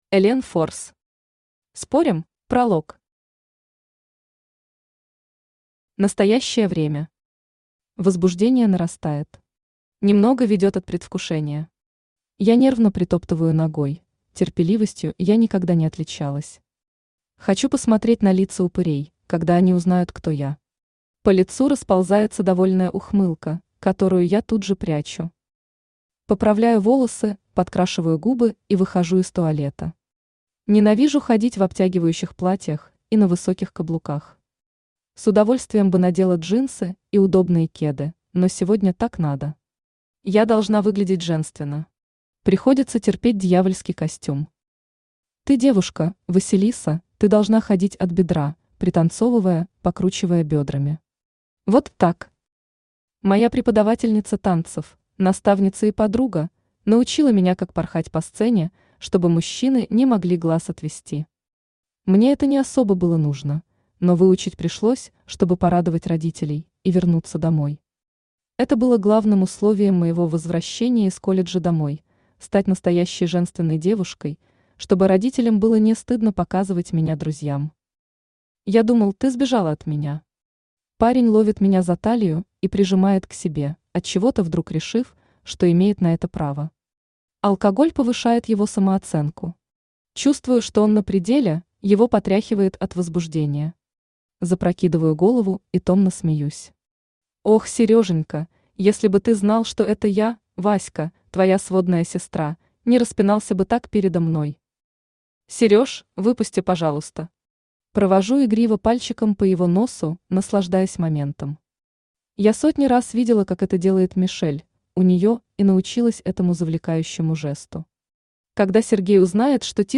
Аудиокнига Спорим?
Автор Элен Форс Читает аудиокнигу Авточтец ЛитРес.